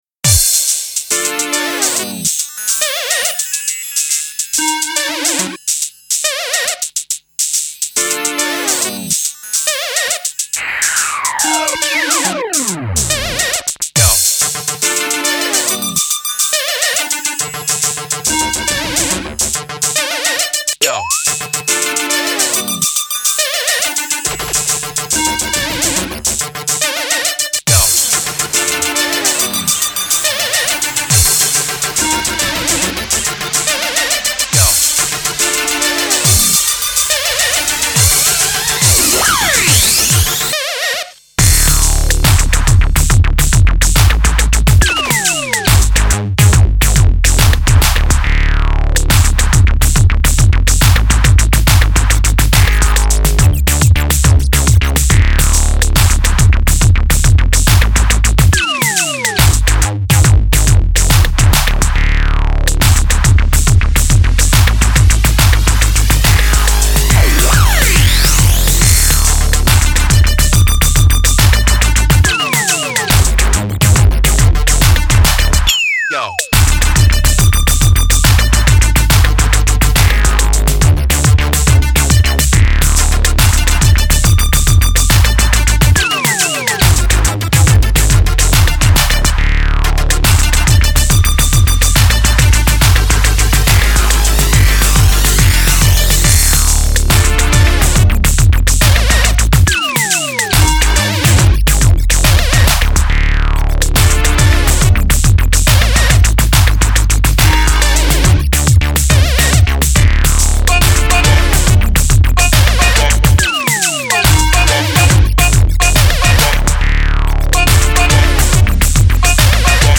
Dubstep
and this one has got a sweeet beat.